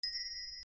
ウインドチャイム
/ G｜音を出すもの / G パーカッション_ウインドチャイム
小 C414